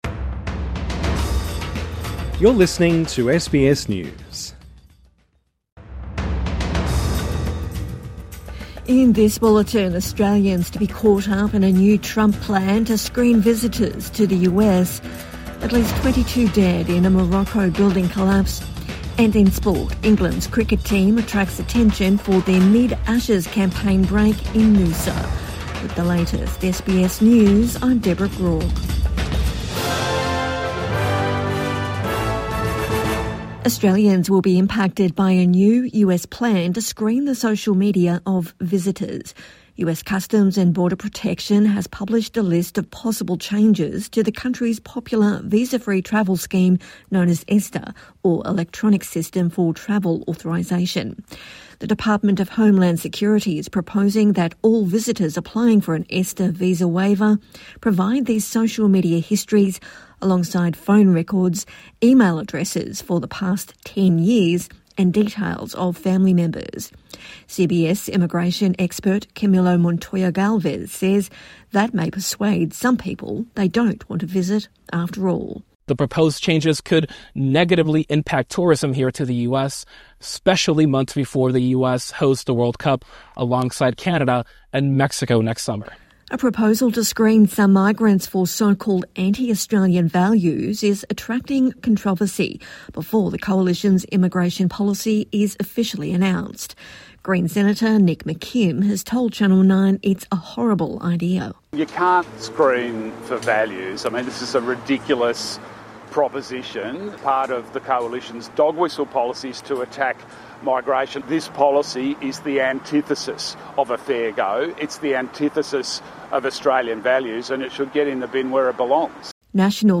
Australians to be caught up in Trump visa plan | Midday News Bulletin 11 December 2025